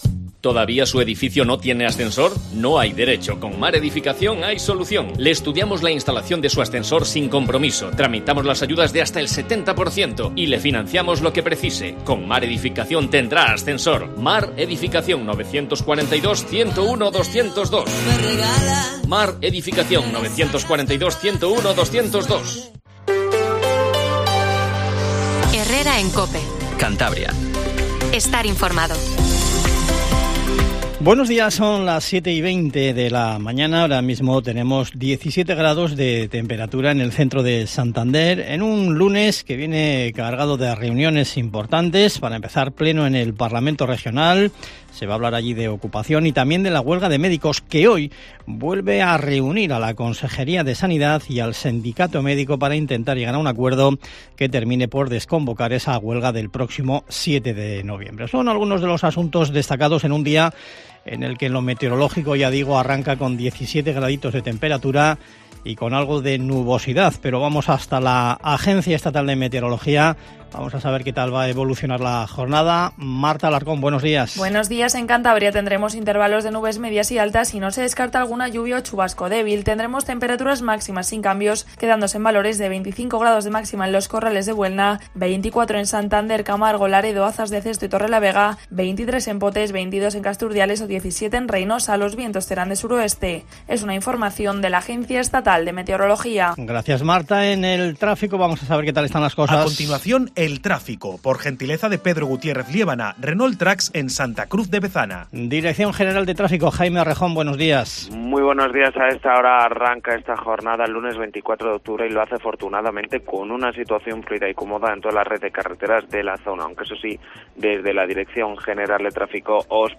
Informativo Matinal Cope